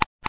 geiger2.ogg